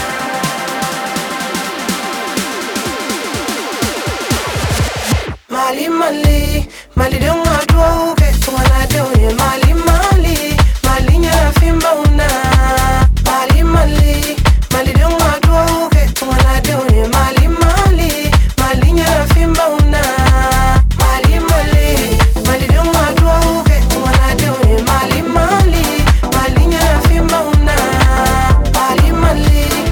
• Electronic